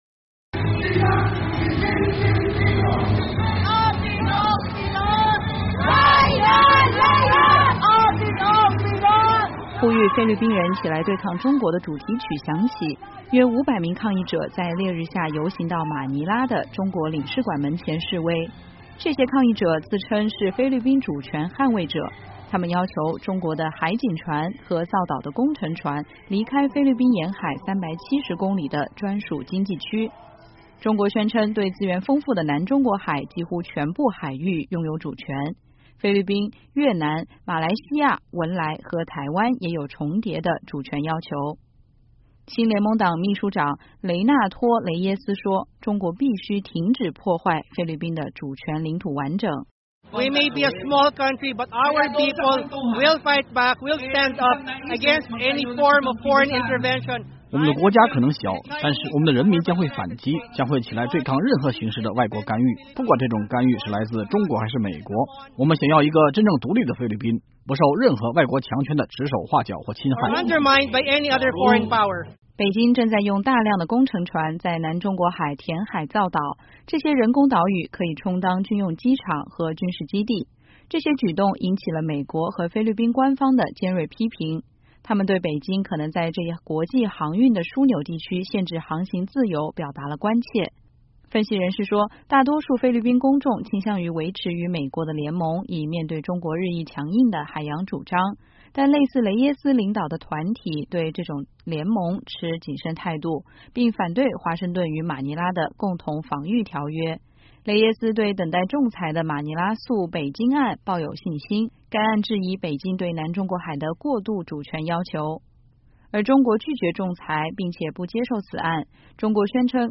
呼吁菲律宾人起来对抗中国的主题曲响起......